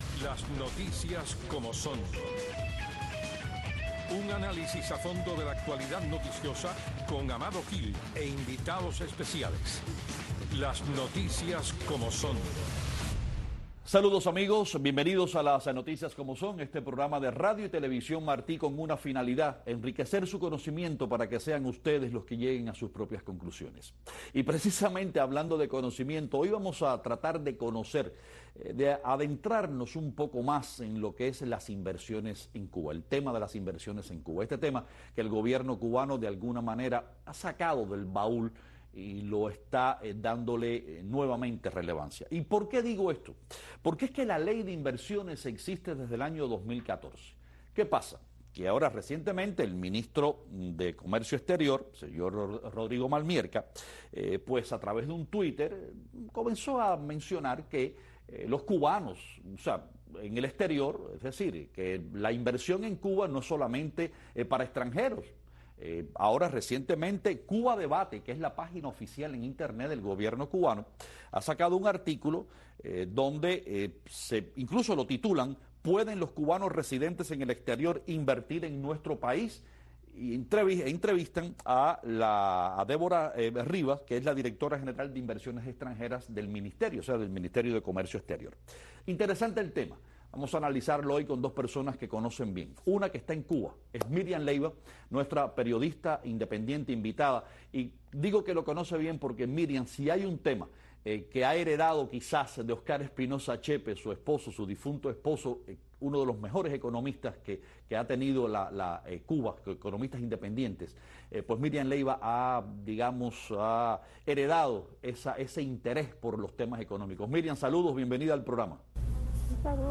desde La Habana.